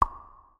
SFX_Dialog_01.wav